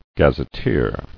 [gaz·et·teer]